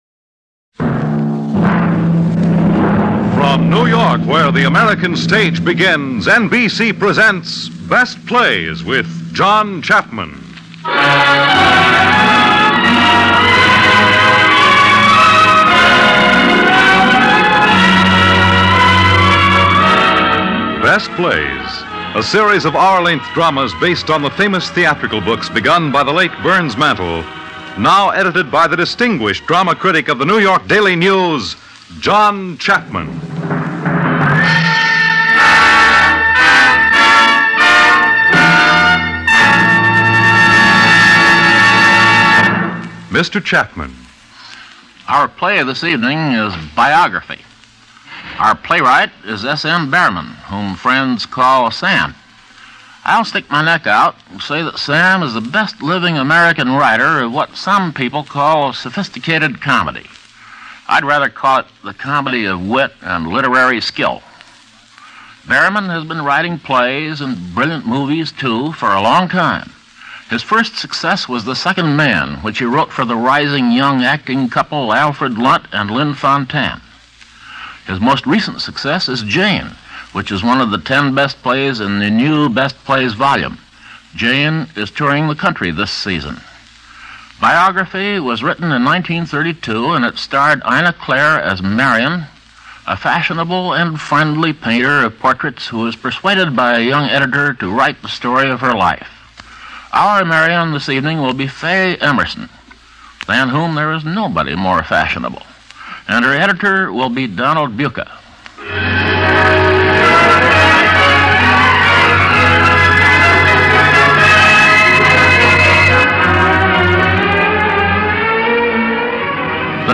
Going on-air from 1952 to 1953, the Best Plays was an NBC Radio program that featured some of the most excellent theatric plays ever created. Some of the best ones featured were dramatic or comedic plays.